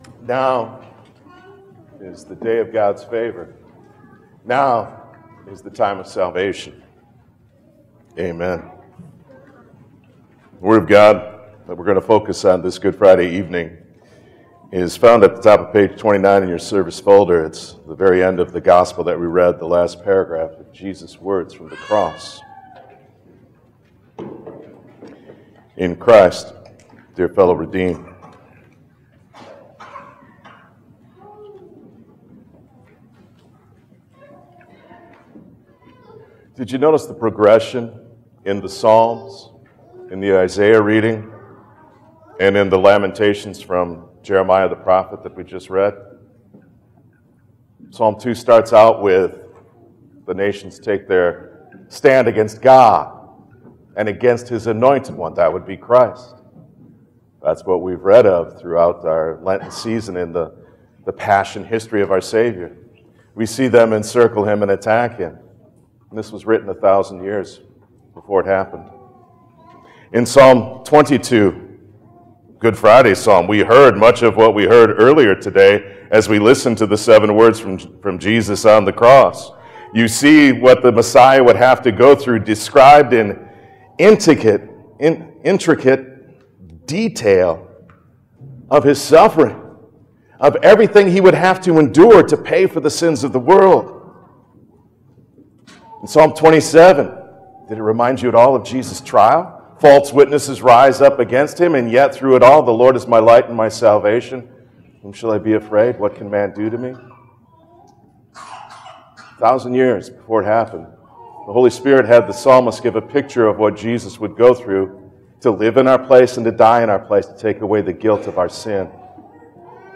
Series: Good Friday